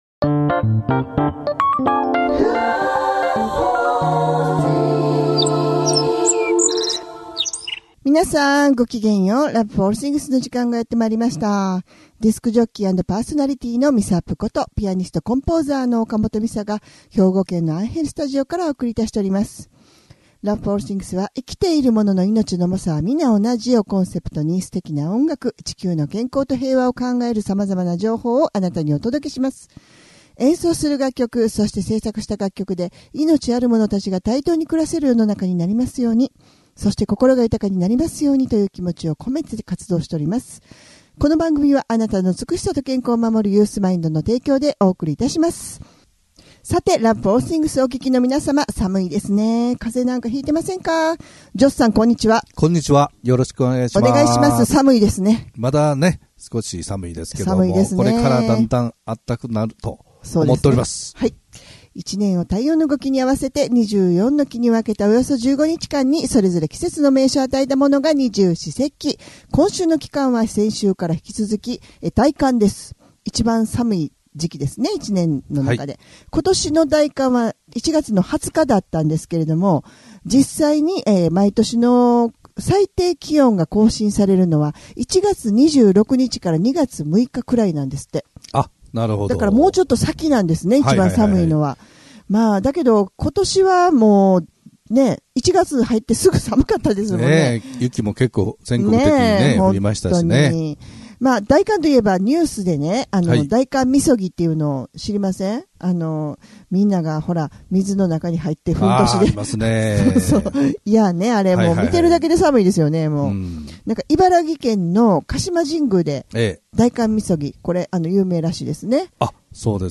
生きているものの命の重さは同じというコンセプトで音楽とおしゃべりでお送りする番組です♪